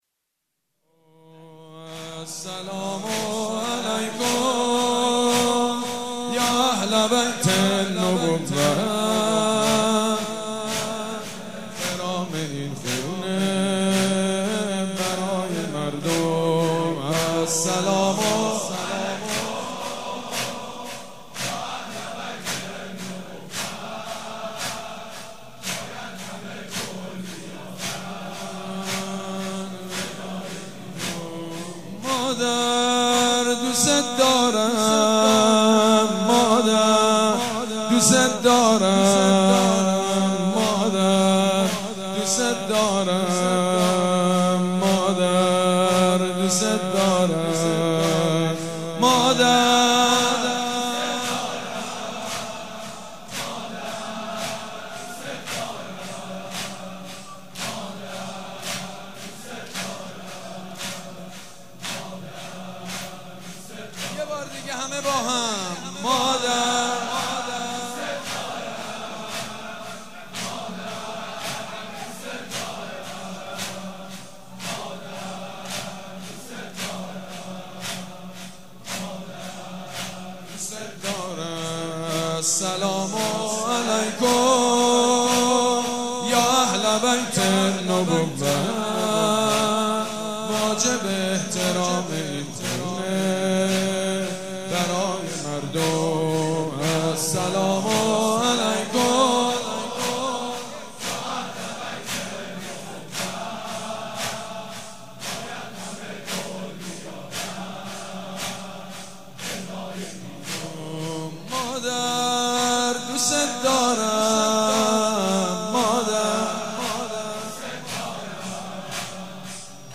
خیمه گاه - عاشقان اهل بیت - زمینه- السلام علیکم یا اهل بیت النبوه- سید مجید بنی فاطمه